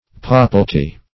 papalty - definition of papalty - synonyms, pronunciation, spelling from Free Dictionary Search Result for " papalty" : The Collaborative International Dictionary of English v.0.48: Papalty \Pa"pal*ty\, n. The papacy.